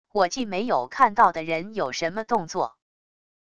我既没有看到的人有什么动作wav音频生成系统WAV Audio Player